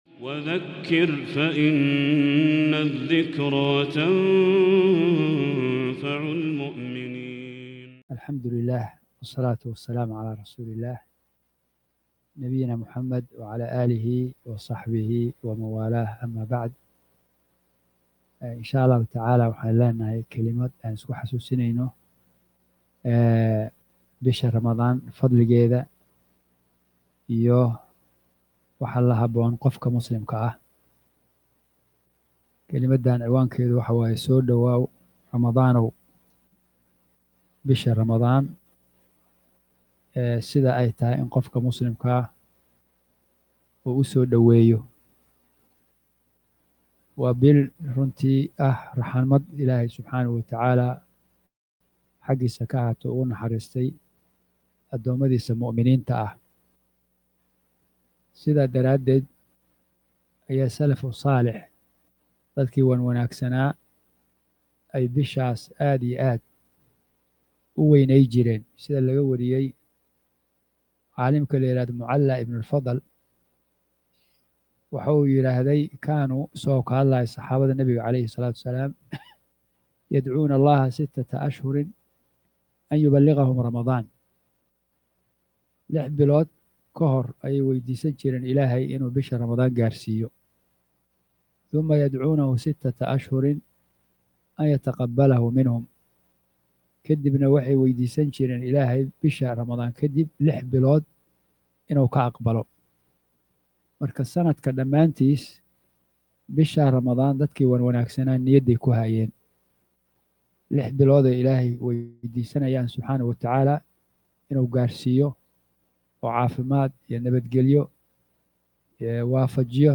Muxaadarooyin